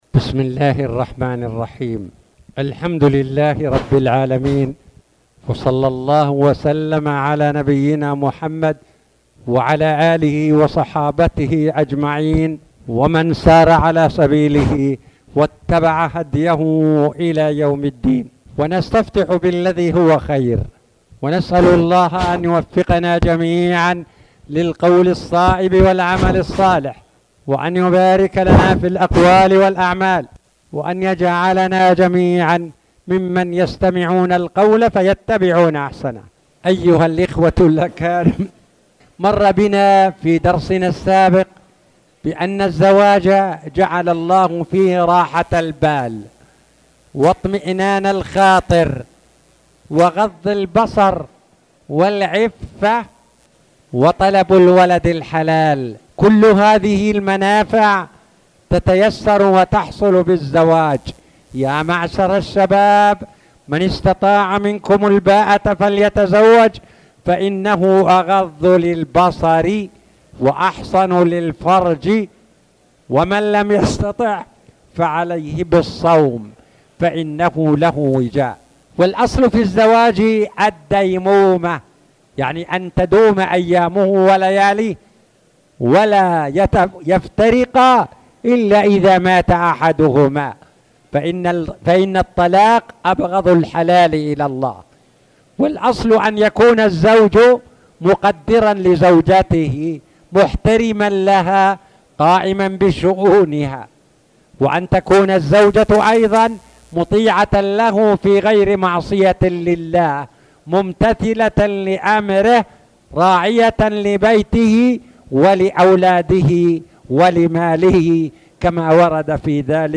تاريخ النشر ١ جمادى الآخرة ١٤٣٨ هـ المكان: المسجد الحرام الشيخ